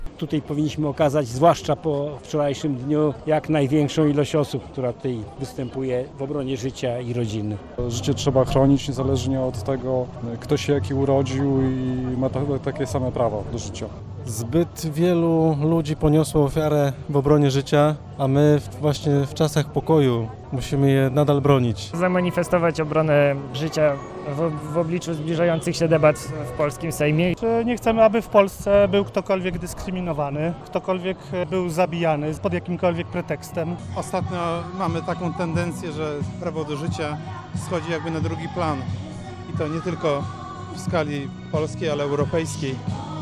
Marsz dla Życia i Rodziny przeszedł przez Warszawę.
Jak mówili naszemu reporterowi, życie i rodzina są dla nich największą wartością.